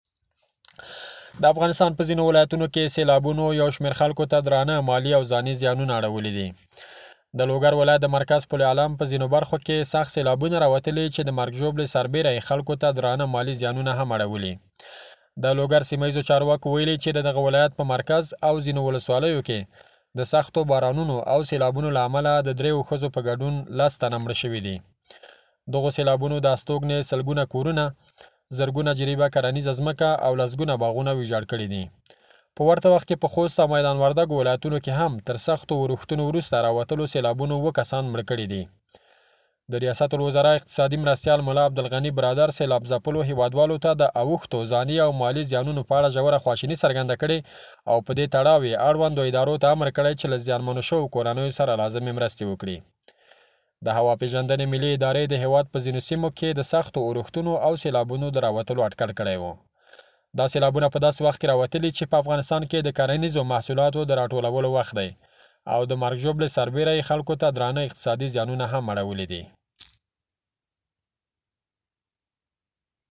نور تفصیل ئې زمونږ دخبریال په راپور کې په ګډه سره آورو .